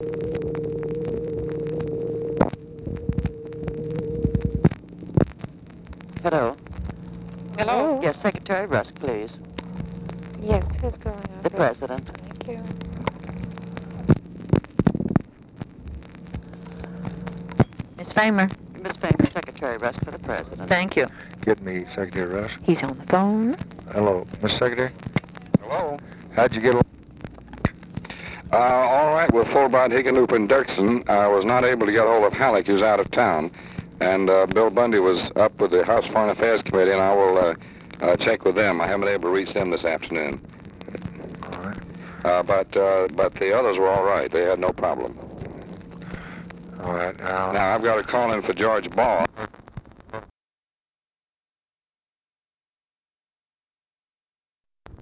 Conversation with DEAN RUSK, June 9, 1964
Secret White House Tapes